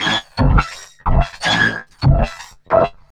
Index of /90_sSampleCDs/USB Soundscan vol.01 - Hard & Loud Techno [AKAI] 1CD/Partition A/08-DROME